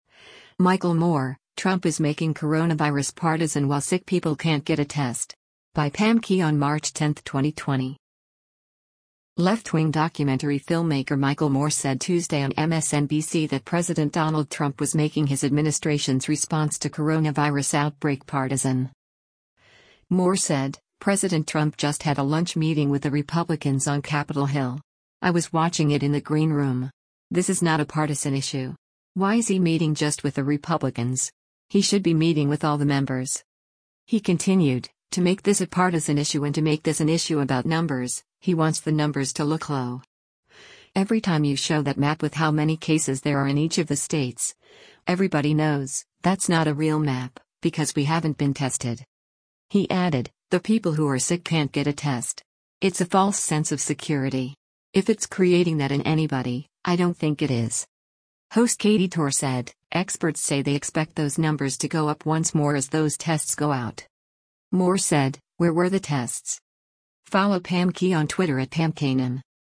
Left-wing documentary filmmaker Michael Moore said Tuesday on MSNBC that President Donald Trump was making his administration’s response to coronavirus outbreak partisan.
Host Katy Tur said, “Experts say they expect those numbers to go up once more as those tests go out.”